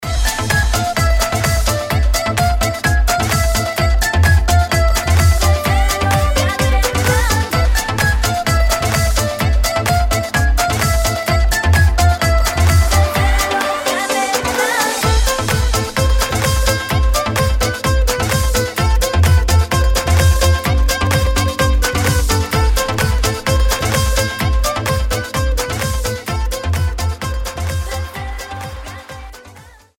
Красивый проигрыш на инструменте